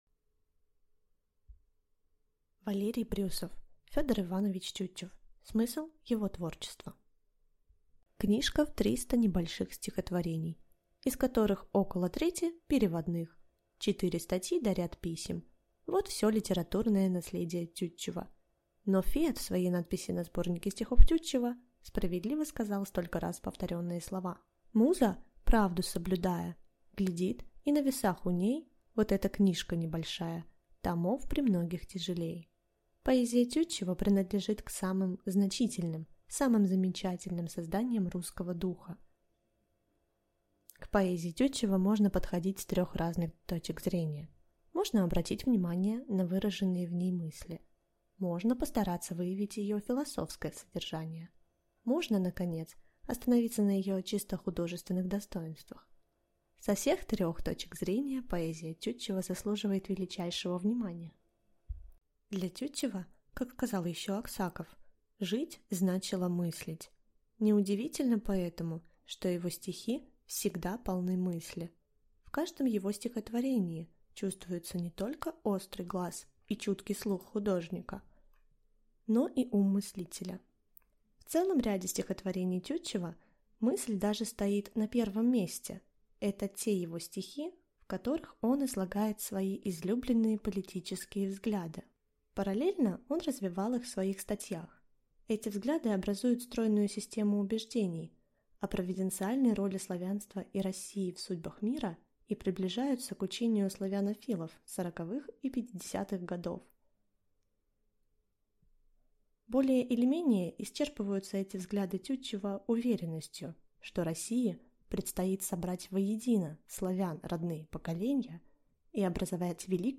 Аудиокнига Ф. И. Тютчев. Смысл его творчества | Библиотека аудиокниг